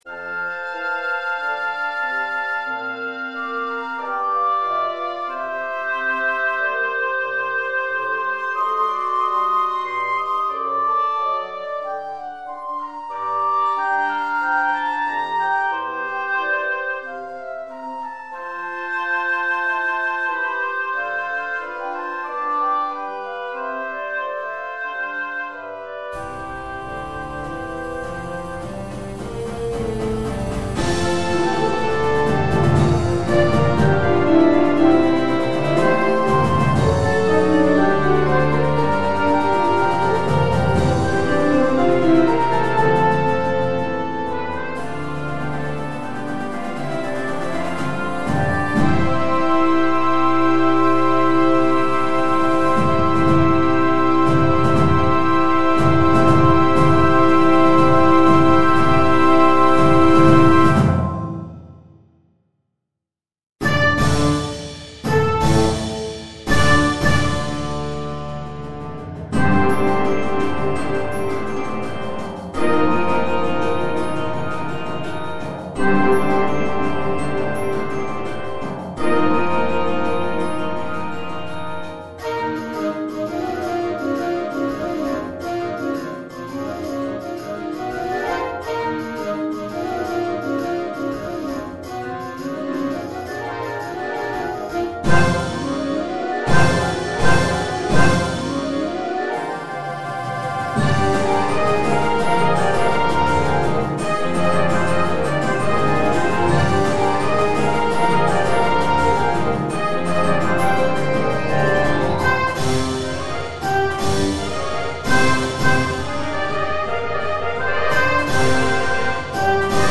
Ecstatic, elevated, euphoric